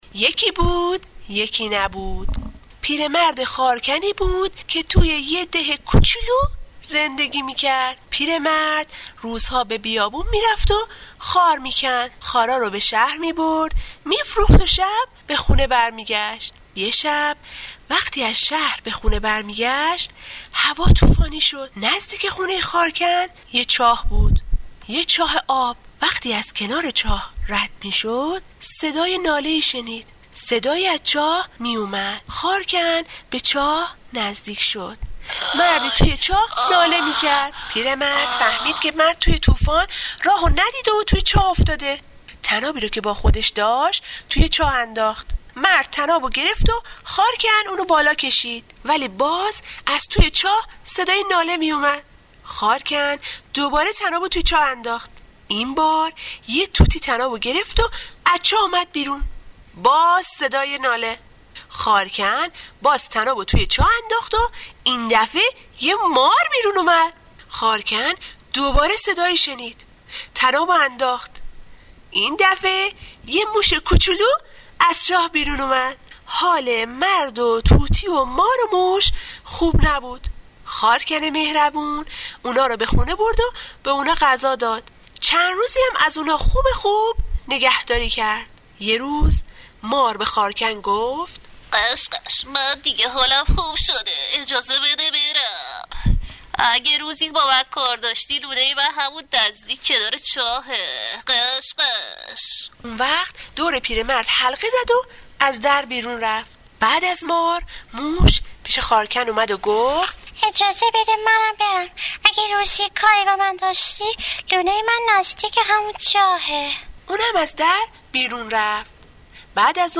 داستان کودکانه داستان کوتاه قصه صوتی خارکن